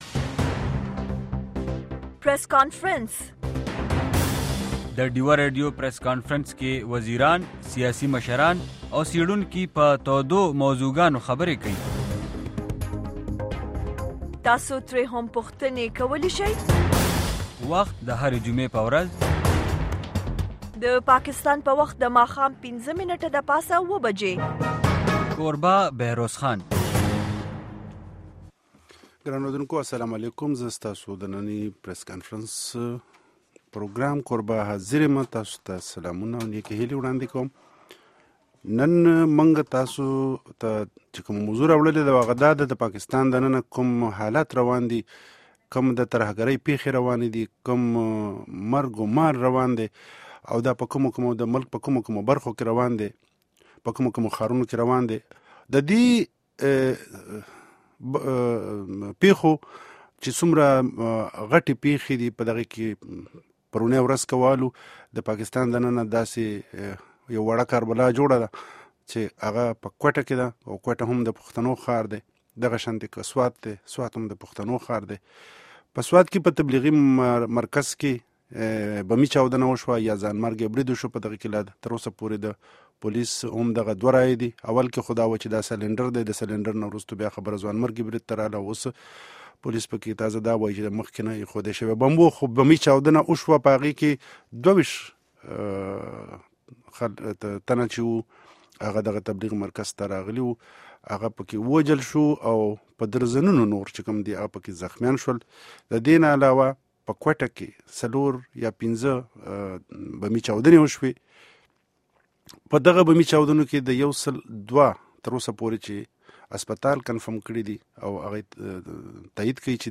Deewa Press Conference on Pakistani Media